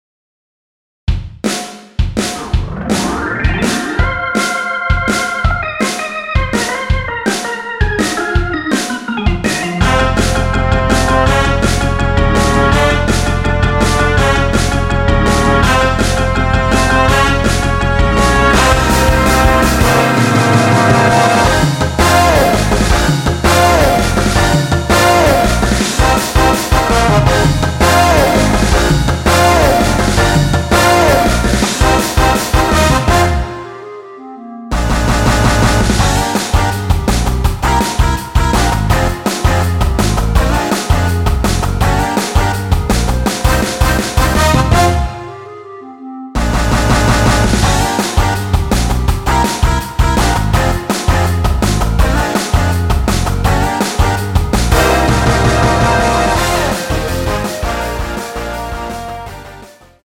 원키에서(-2)내린 멜로디 포함된 MR입니다.
Eb
멜로디 MR이라고 합니다.
앞부분30초, 뒷부분30초씩 편집해서 올려 드리고 있습니다.